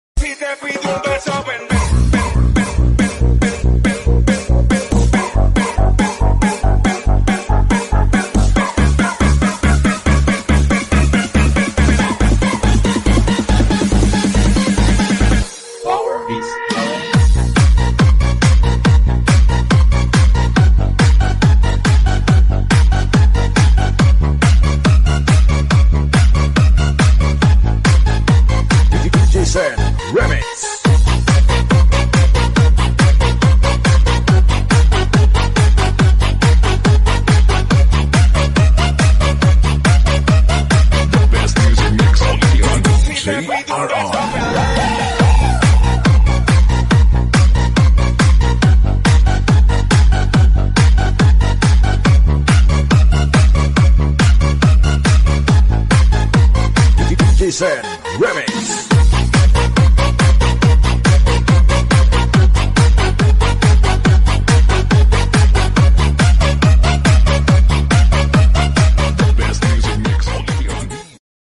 full remix p0wer beat